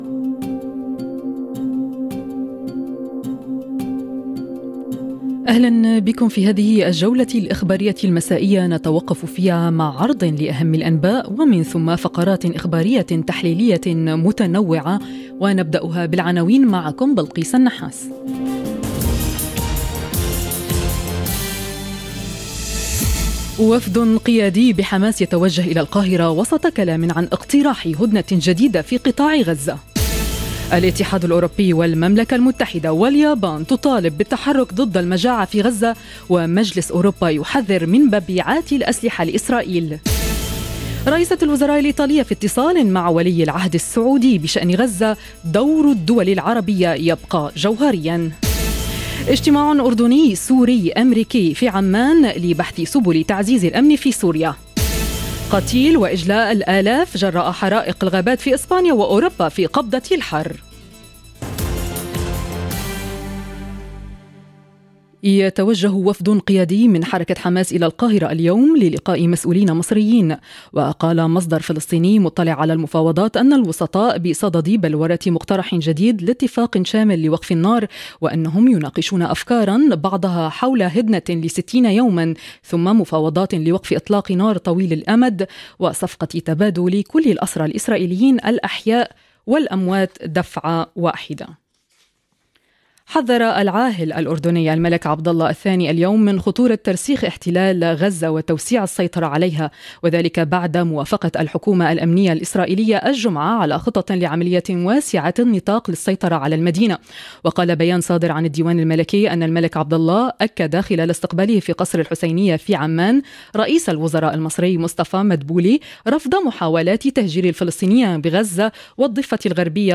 نشرة أخبار المساء: وفد قيادي في حماس يتوجه الى القاهرة وسط كلام عن اقتراح هدنة جديد في قطاع غزة - Radio ORIENT، إذاعة الشرق من باريس